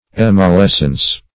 Search Result for " emollescence" : The Collaborative International Dictionary of English v.0.48: Emollescence \Em`ol*les"cence\, n. [L. e out + mollescere, incho. fr. mollere to be soft, mollis soft.] That degree of softness in a body beginning to melt which alters its shape; the first or lowest degree of fusibility.
emollescence.mp3